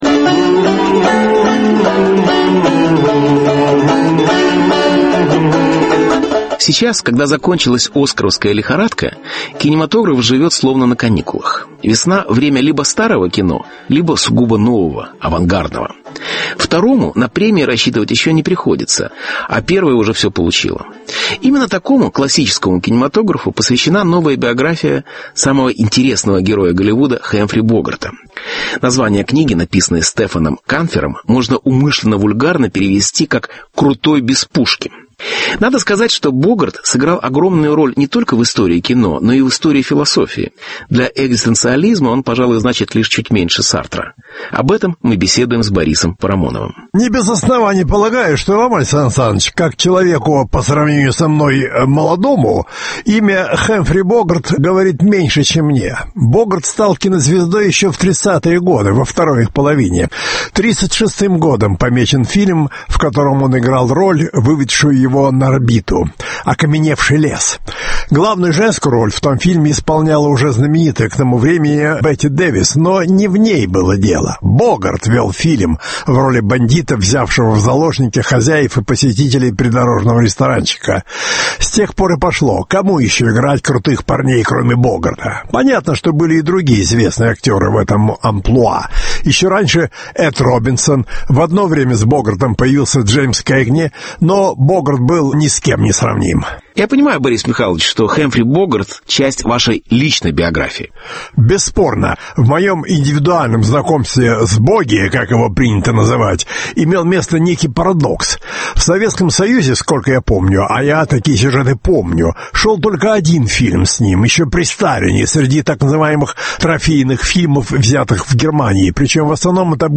Миф Хэмфри Богарта. Беседа с Борисом Парамоновым.